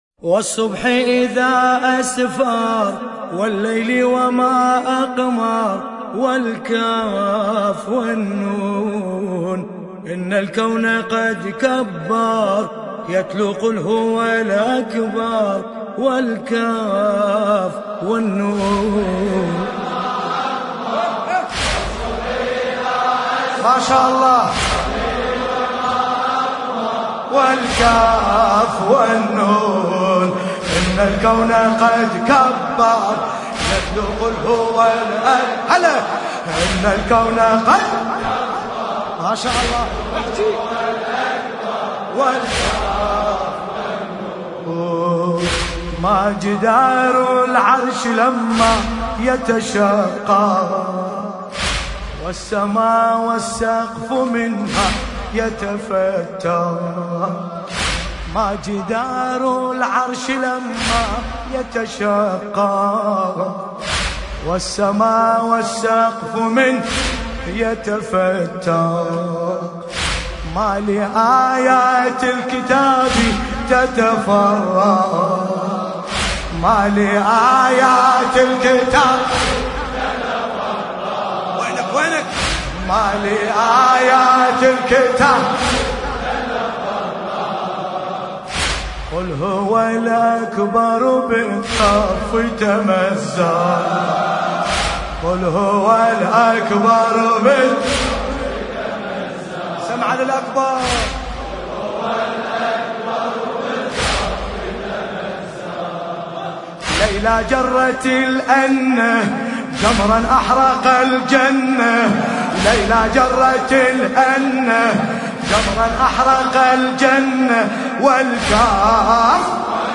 ملف صوتی قل هو الأكبر بصوت باسم الكربلائي
الرادود : الحاج ملا باسم الكربلائي